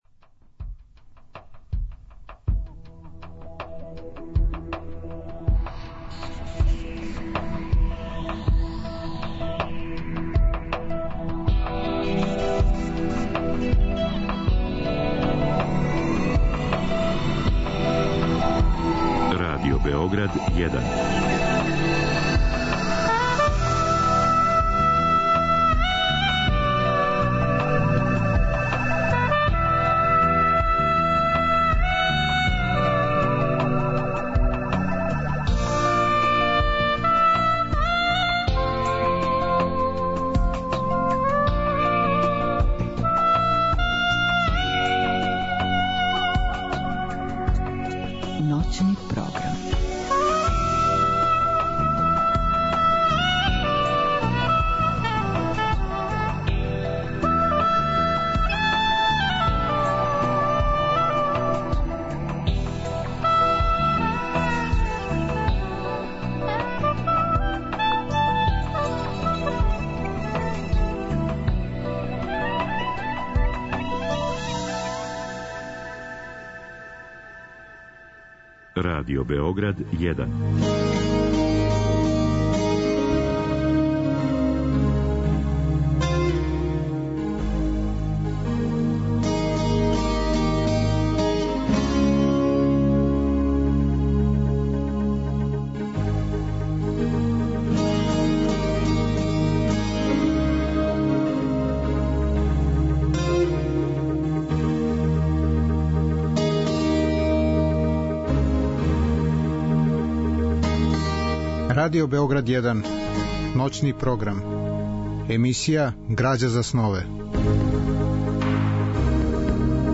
Разговор и добра музика требало би да кроз ову емисију и сами постану грађа за снове.
У 2. делу емисије, од сада па до 4 часа ујутро, слушаћемо одабране делове из радио-драма рађених по роману Сеобе Милоша Црњанског.